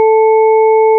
Sur les différentes représentations des courbes suivantes, les fréquences et les amplitudes sont identiques.
une harmonique s'ajoute sur la courbe parfaite